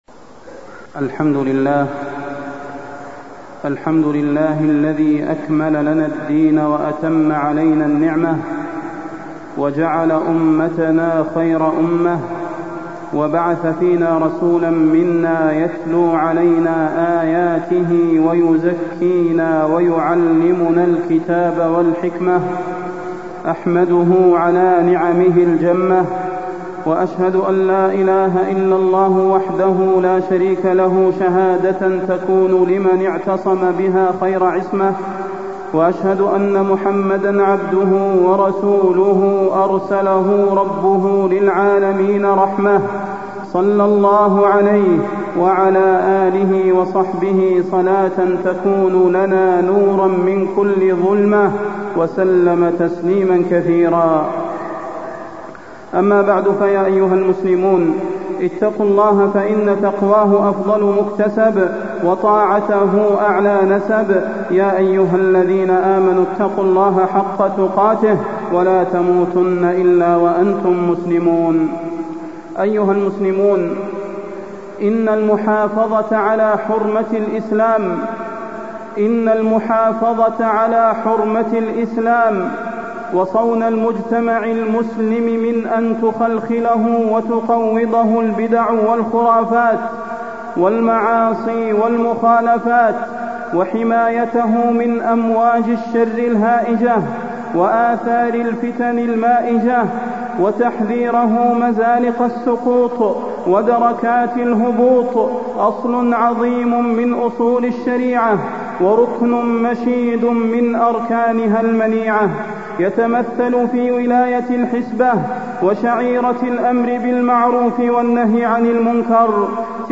فضيلة الشيخ د. صلاح بن محمد البدير
تاريخ النشر ٧ جمادى الأولى ١٤٢٥ هـ المكان: المسجد النبوي الشيخ: فضيلة الشيخ د. صلاح بن محمد البدير فضيلة الشيخ د. صلاح بن محمد البدير دور هيئة الأمر بالمعروف والنهي عن المنكر